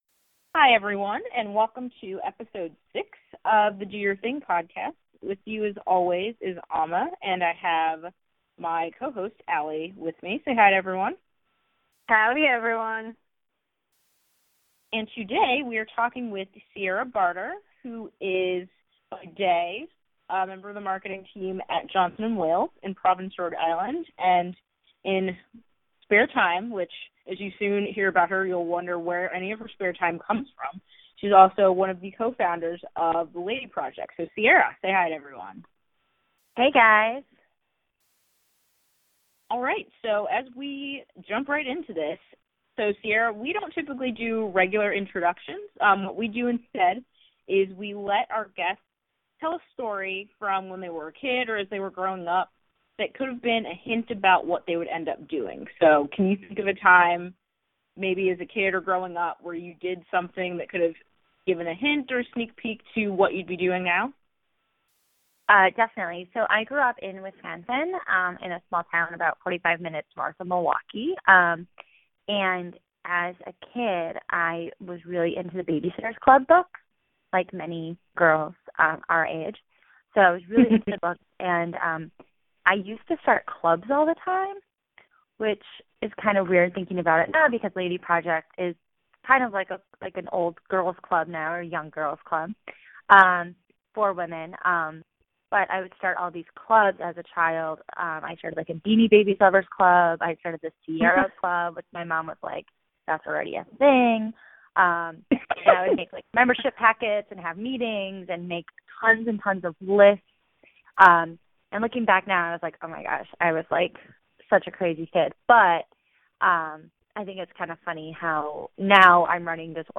Plus, we get our first canine cameos!